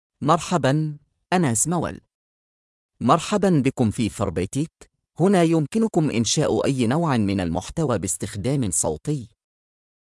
Ismael — Male Arabic (Algeria) AI Voice | TTS, Voice Cloning & Video | Verbatik AI
MaleArabic (Algeria)
Ismael is a male AI voice for Arabic (Algeria).
Voice sample
Ismael delivers clear pronunciation with authentic Algeria Arabic intonation, making your content sound professionally produced.